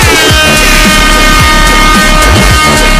air horn 420 style
air-horn-420-style.mp3